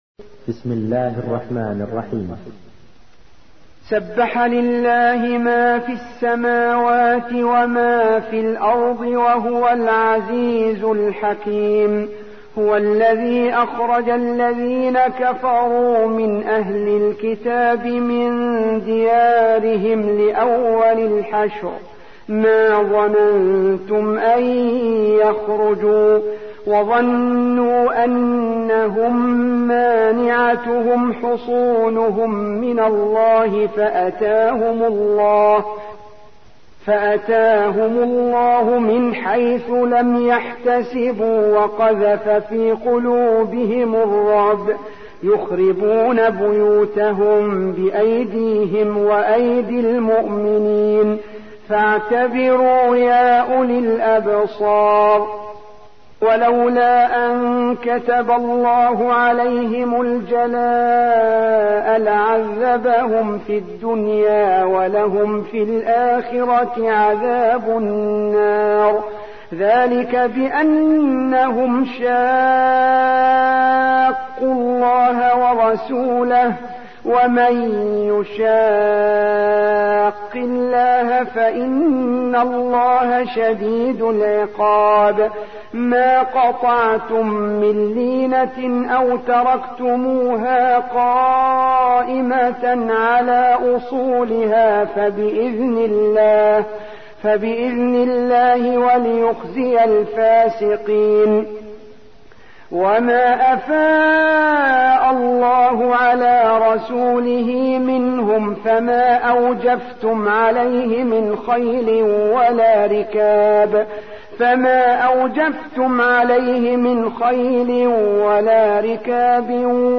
59. سورة الحشر / القارئ